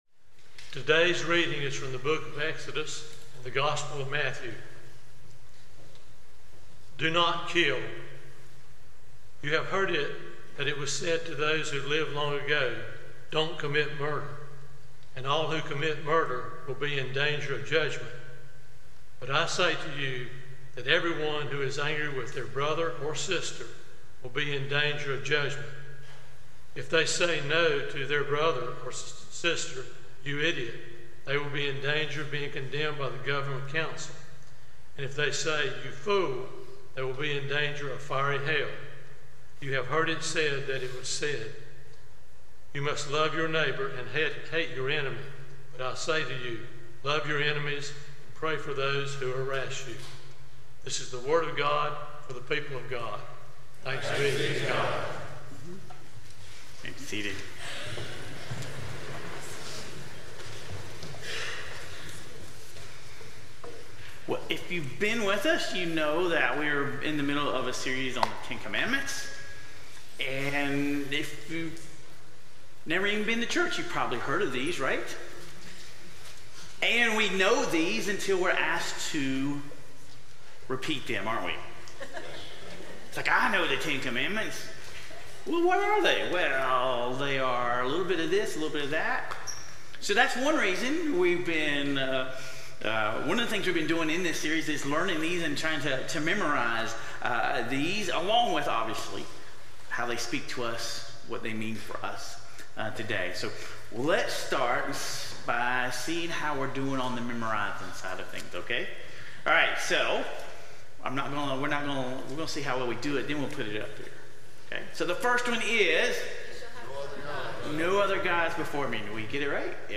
This Sunday, we continue our new year worship series, “10.”